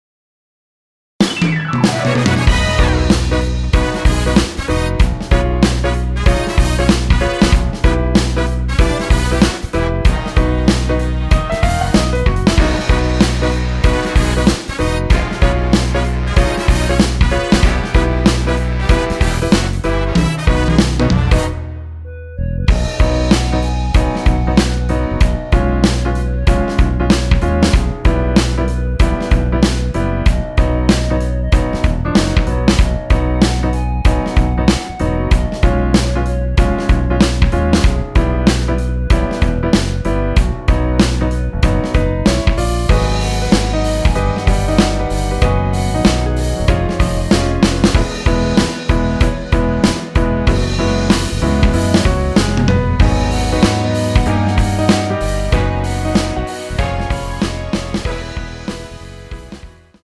원키에서(-3)내린 멜로디 포함된 MR입니다.(미리듣기 확인)
앞부분30초, 뒷부분30초씩 편집해서 올려 드리고 있습니다.
중간에 음이 끈어지고 다시 나오는 이유는